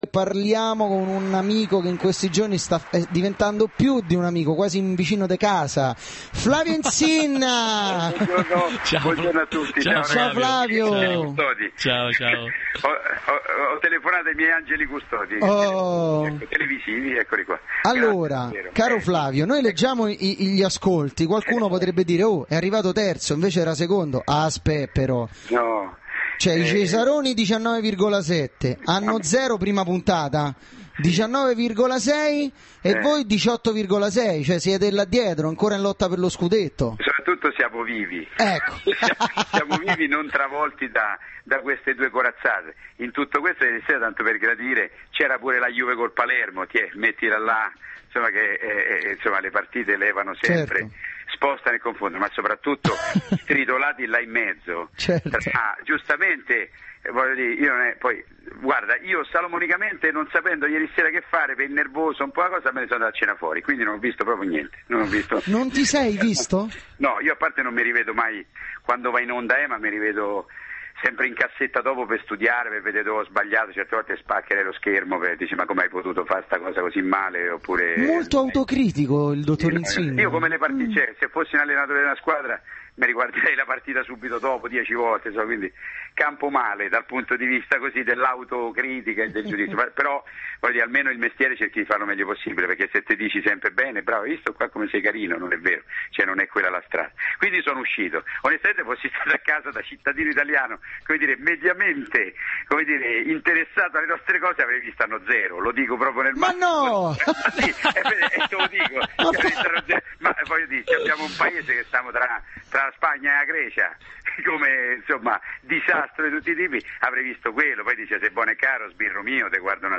Intervento telefonico Flavio Insinna e Paolo Bonolis del 24/09/2010
ascolta_lintervento_di_flavio_insinna_e_polo_bono.mp3